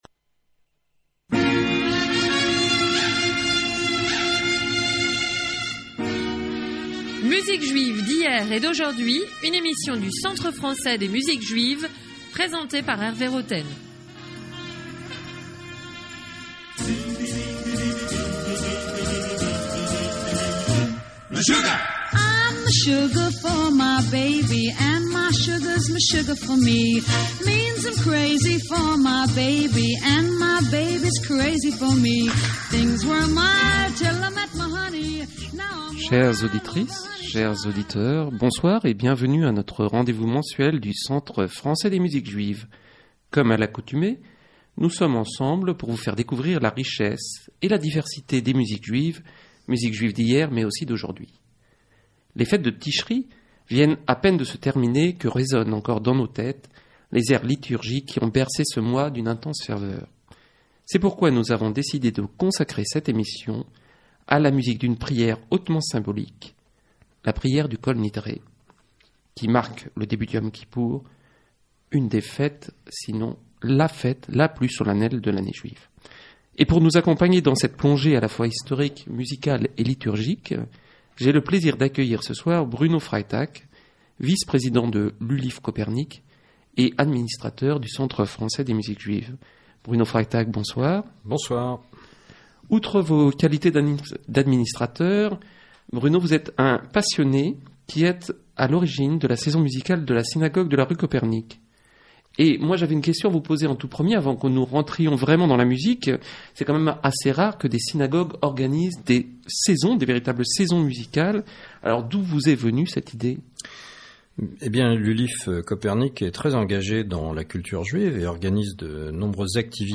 Une émission de radio du Centre Français des Musiques Juives